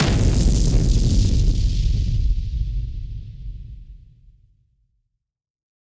BF_HitSplosionB-01.wav